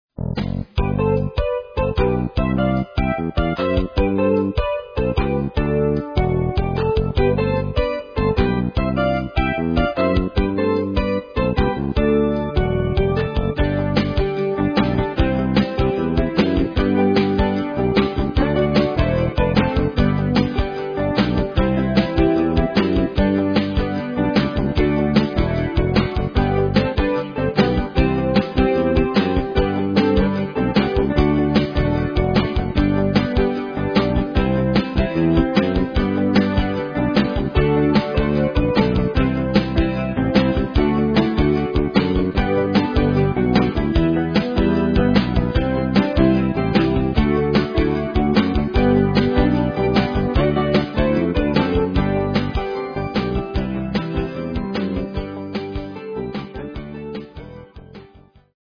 All Charts are 7 piece
Piano
Bass
Drums
Guitar
Trumpet
Tenor
Trombone